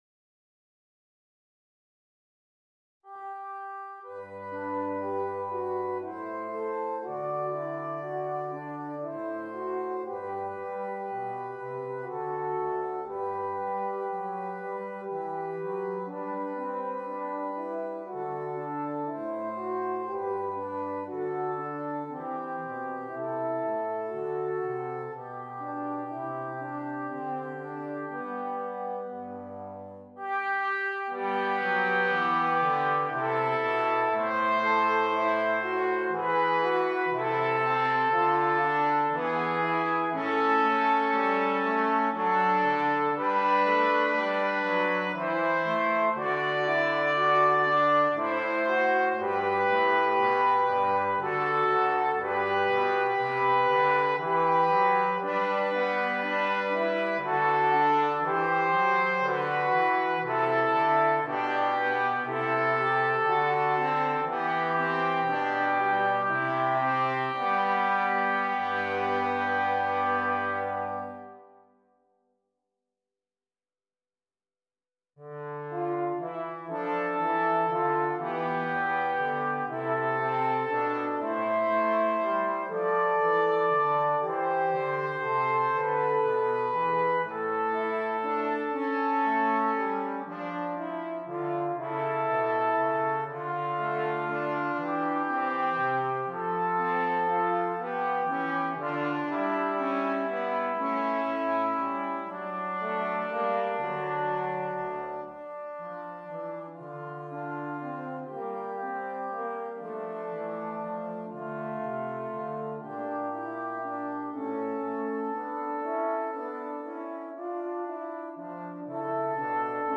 Brass Trio